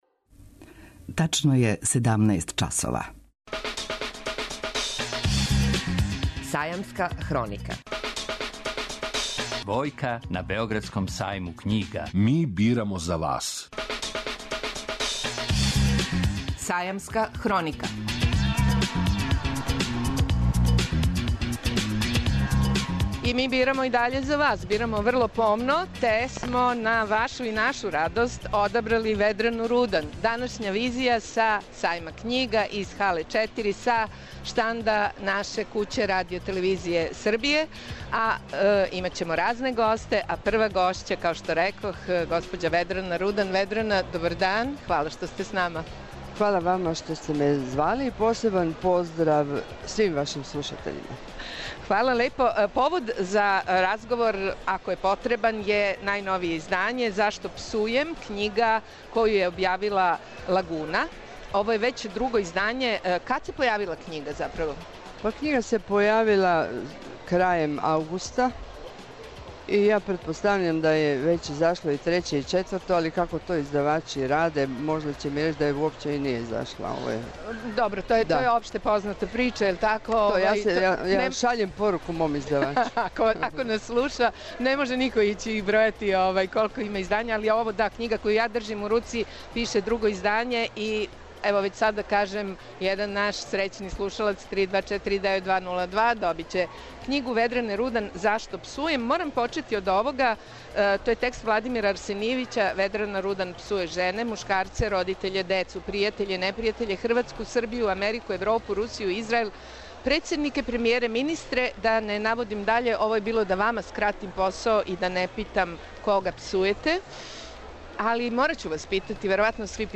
преузми : 55.63 MB Визија Autor: Београд 202 Социо-културолошки магазин, који прати савремене друштвене феномене.